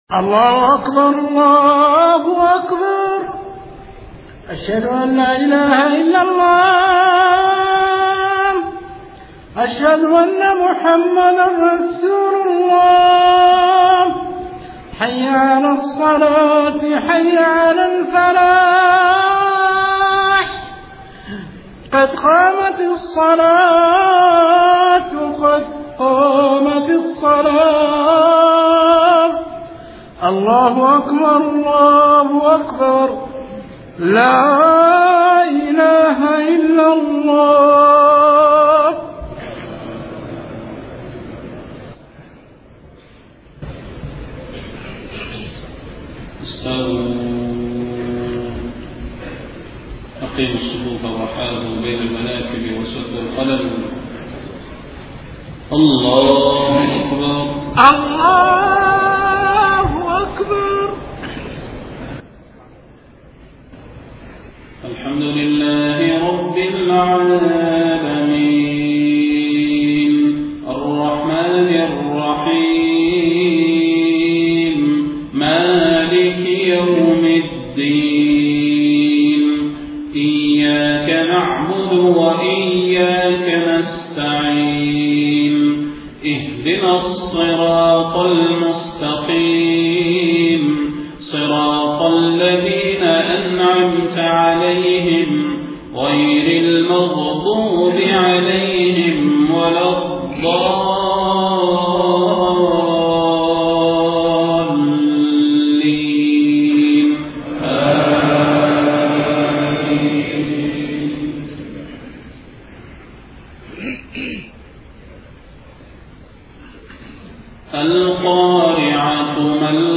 صلاة المغرب 4 ربيع الأول 1431هـ سورتي القارعة و التكاثر > 1431 🕌 > الفروض - تلاوات الحرمين